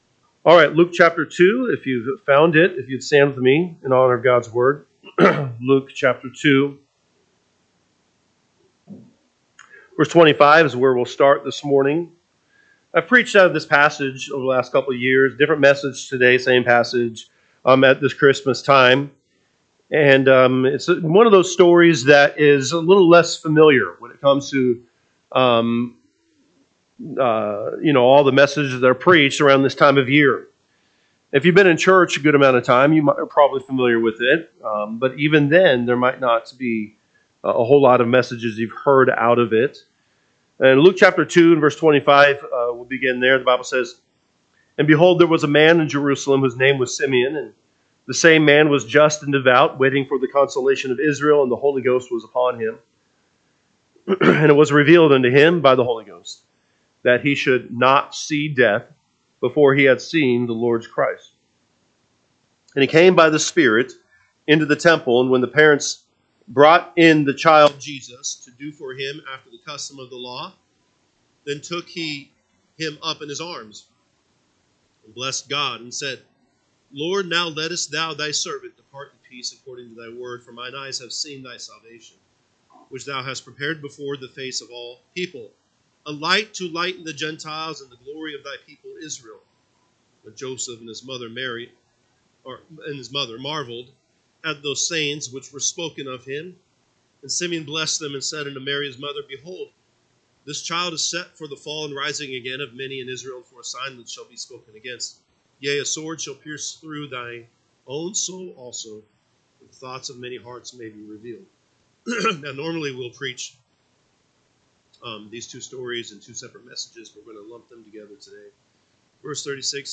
December 1, 2024 am Service Luke 2:25-40 (KJB) 25 And, behold, there was a man in Jerusalem, whose name was Simeon; and the same man was just and devout, waiting for the consolation of Israel:…